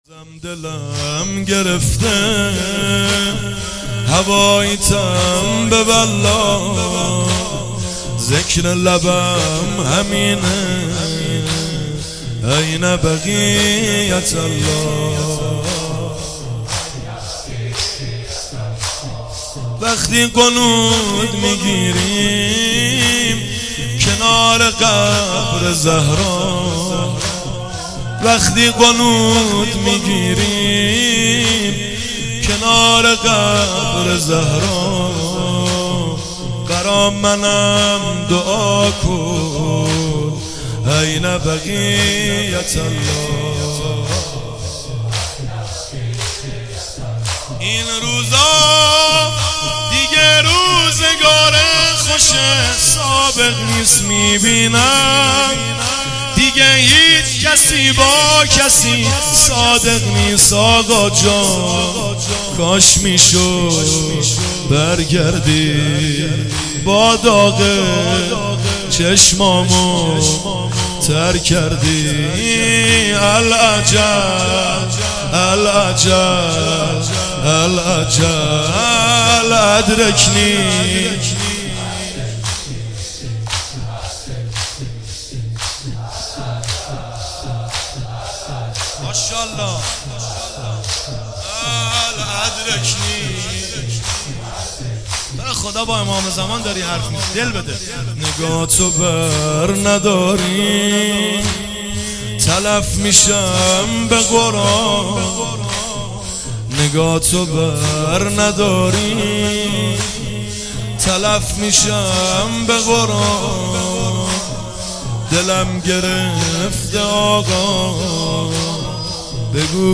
مراسم هفتگی 20 خرداد 99 هیات جان نثاران امام زمان(عج)
مداحی
شور